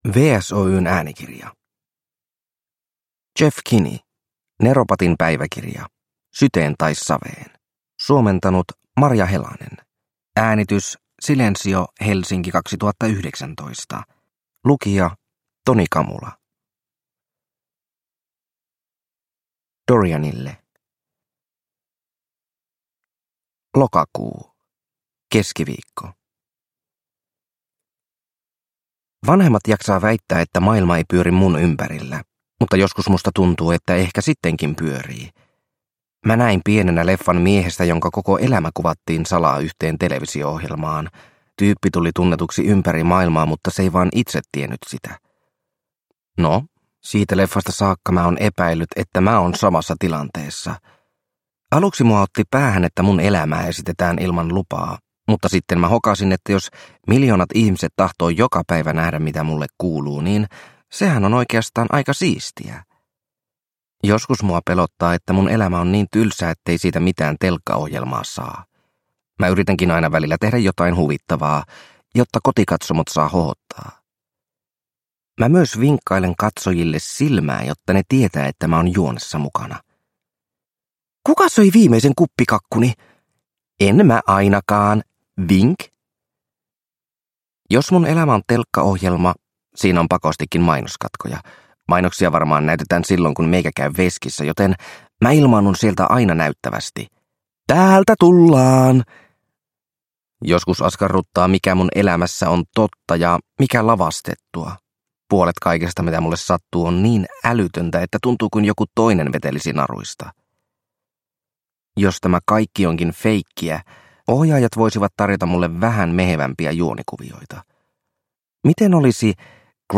Neropatin päiväkirja: Syteen tai saveen – Ljudbok – Laddas ner